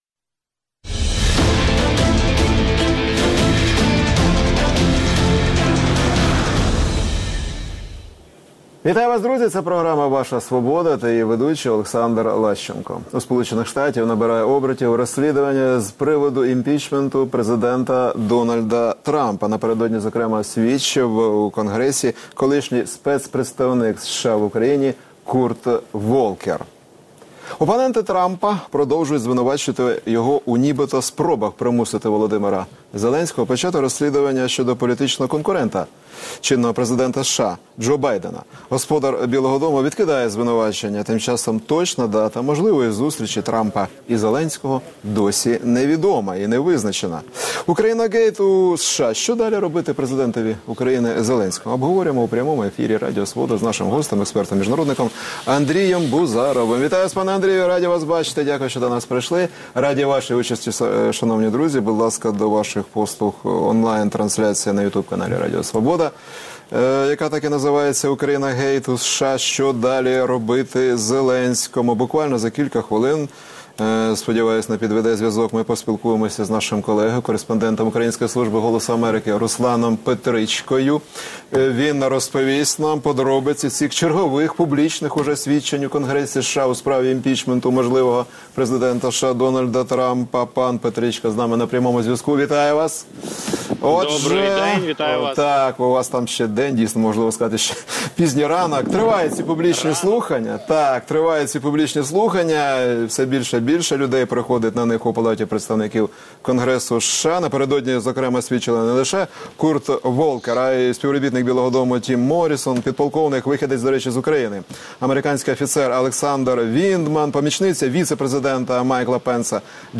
скайпом